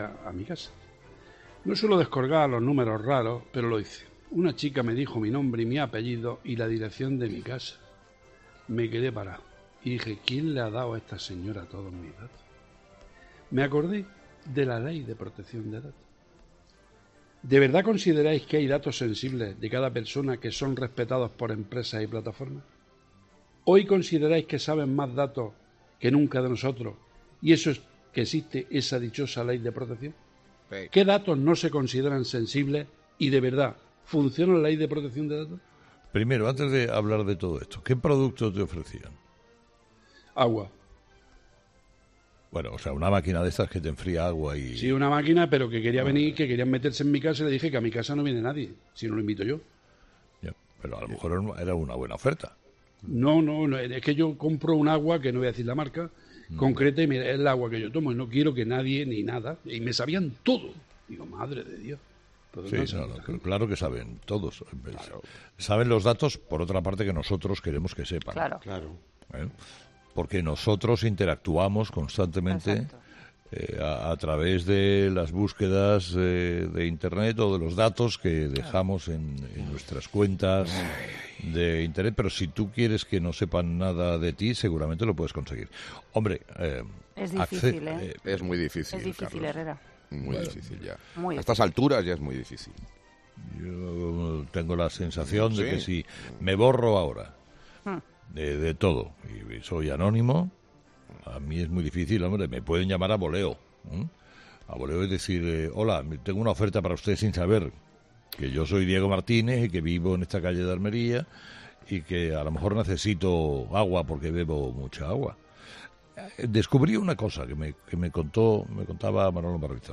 Muchos son los remedios que se explican en Internet para evitar que ser asaltado por quienes de una forma u otra han conseguido nuestro número de móvil, pero pocas veces alguien como Carlos Herrera cuenta en directo un truco infalible para evitar ser molestado con propuestas comerciales telefónicas de este tipo.
El resto de contertulios ha querido analizar desde su propio prisma cómo ven el tema.